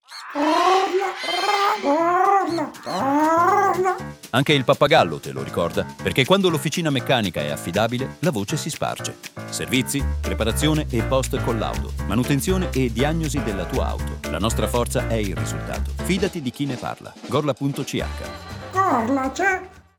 Spot radio GARAGE
Lo stesso approccio è stato adattato alla radio con spot pensati per Radio 3i, dove il suono e la voce del pappagallo renderanno immediatamente riconoscibile il messaggio anche senza immagini.